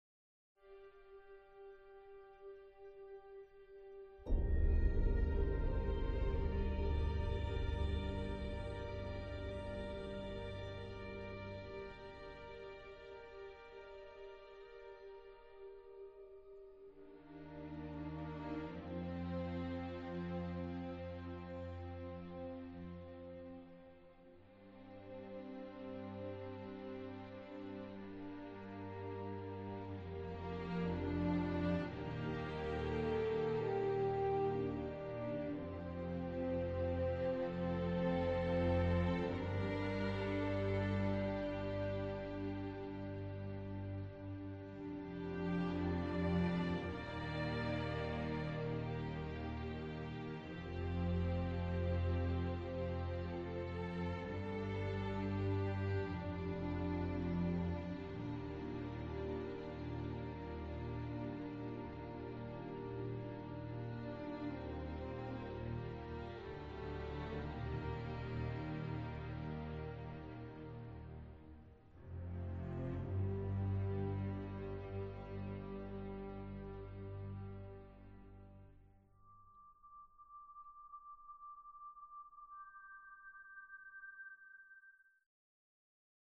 Soundtrack, Orchestral, Film Score